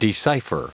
Prononciation du mot decipher en anglais (fichier audio)
Prononciation du mot : decipher